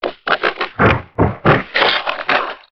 ALIEN_Communication_30_mono.wav